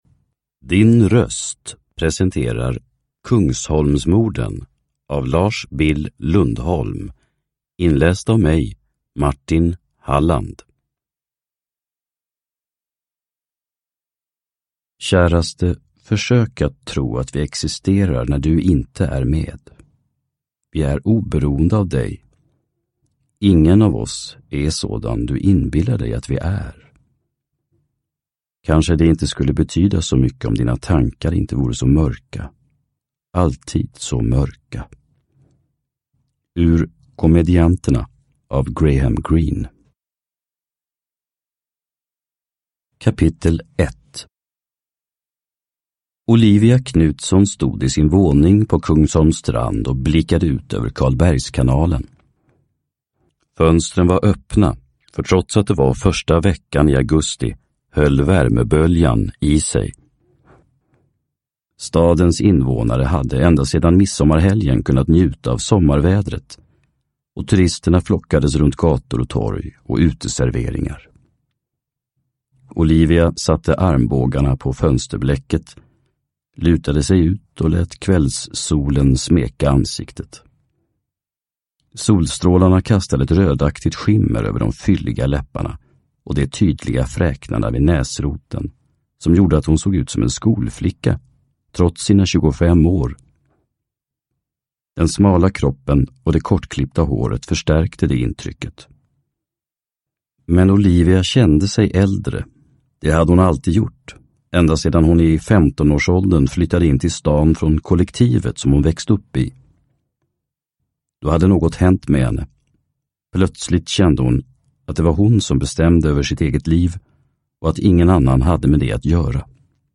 Kungsholmsmorden / Ljudbok